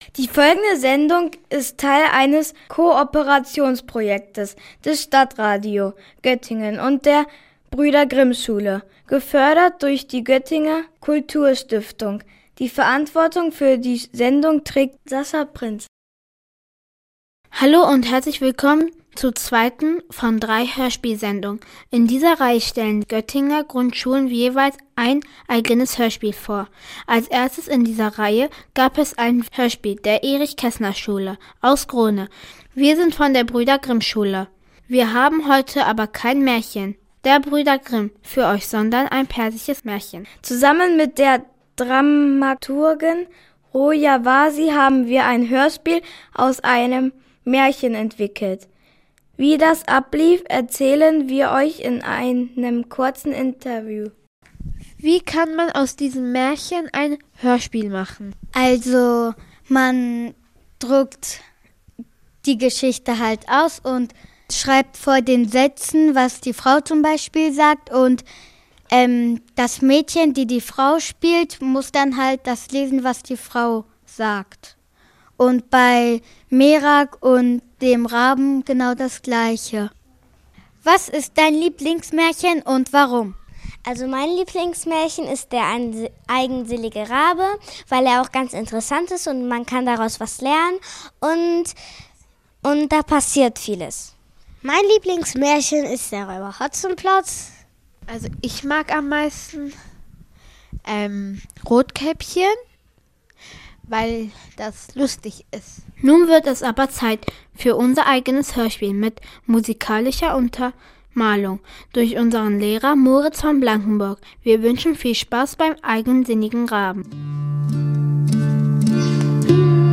Hörspiel: Der eigensinnige Rabe